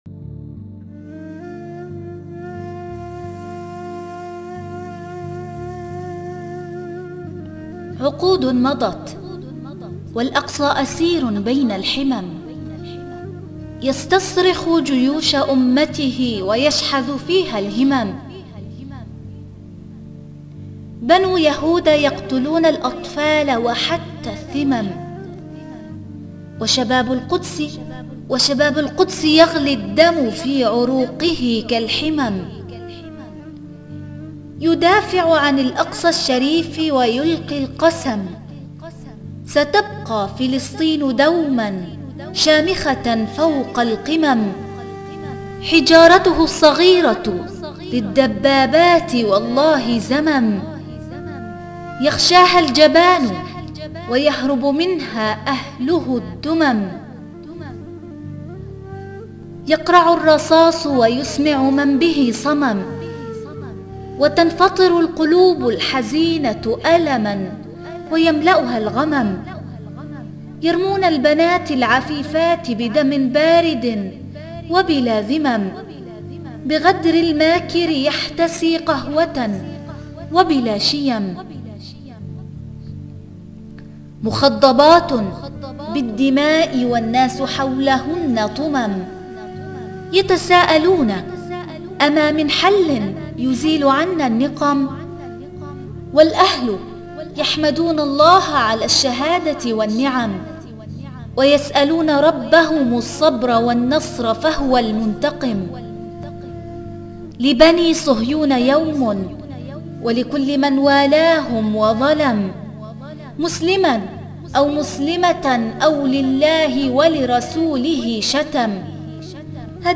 قصيدة - لا تحزن يا أقصى جيوش أمتك ستحررك